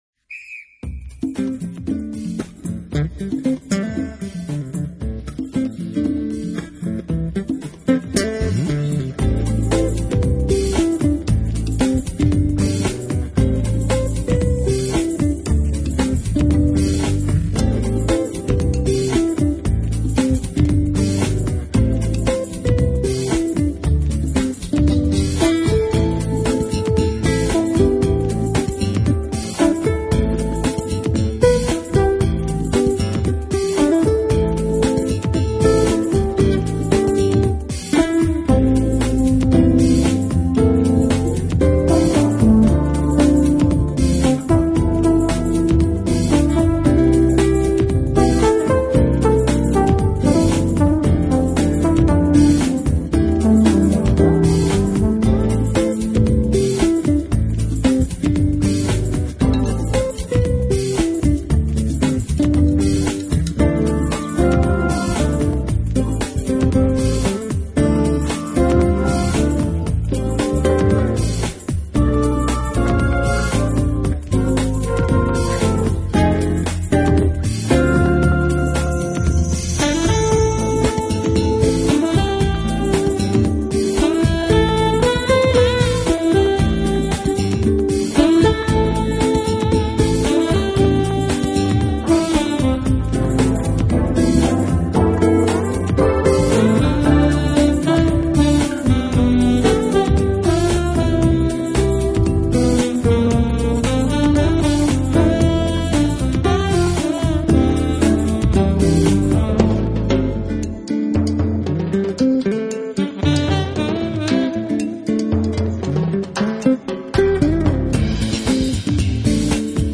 专辑类型：JAZZ